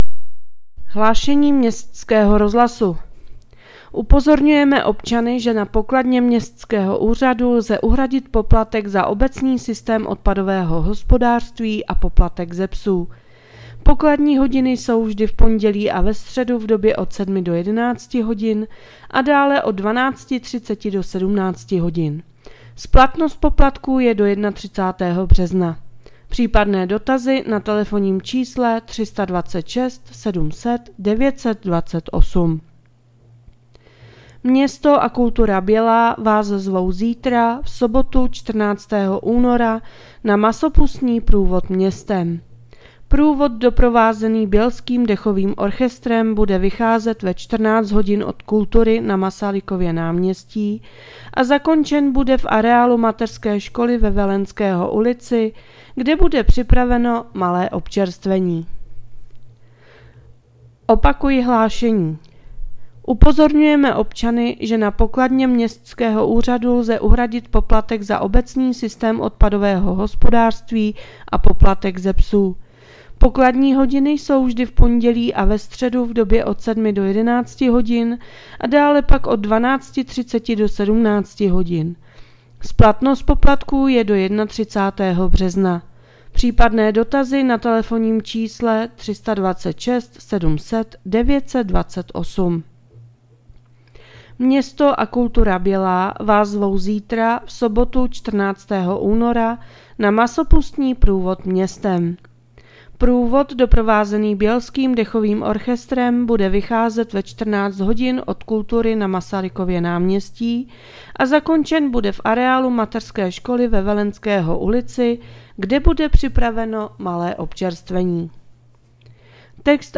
Hlášení městského rozhlasu 13.2.2026